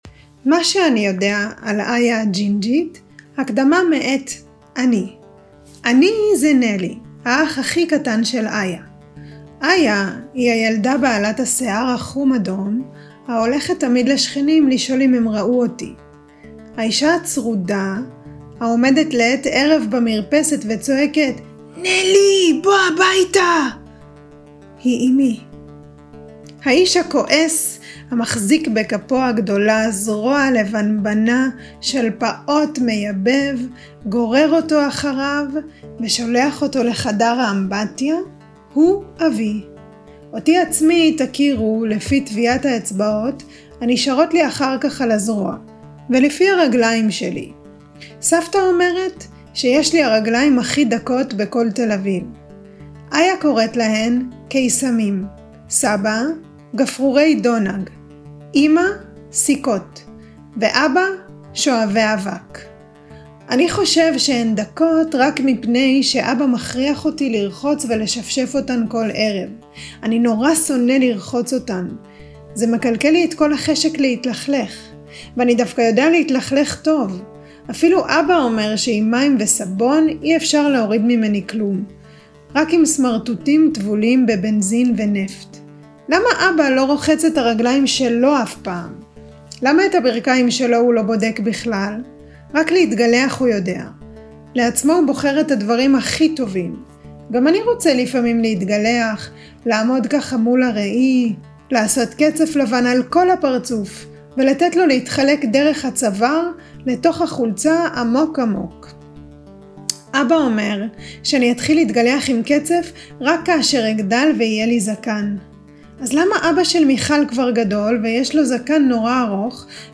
אני קוראת את הסיפורים שאנחנו לומדים מתוך הספר "איה הג'ינג'ית"